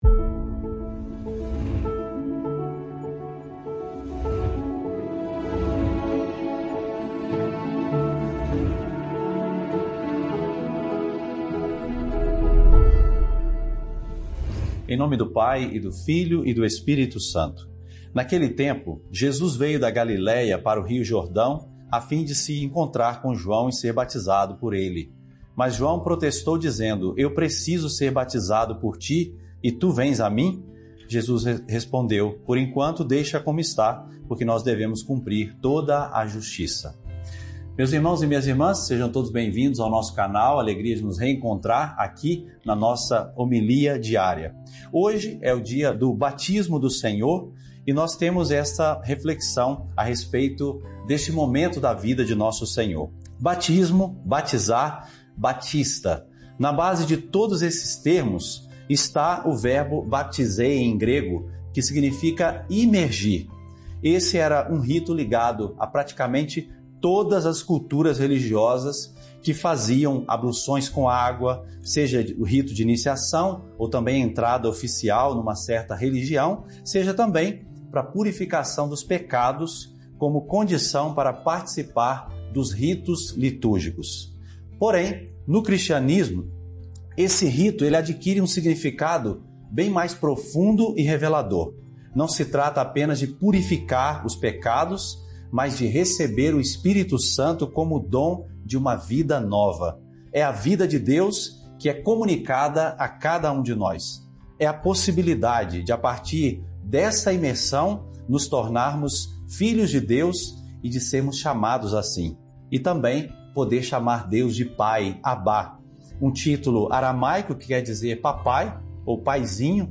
Alegria por nos reencontrarmos na nossa homilia diária!